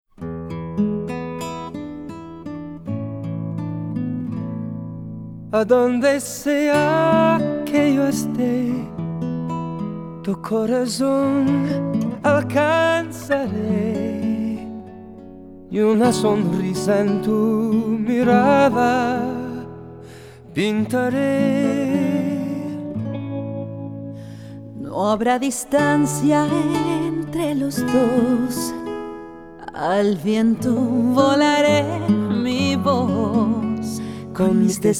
# Holiday